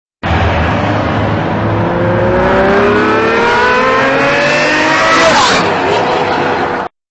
0-60 Ferrari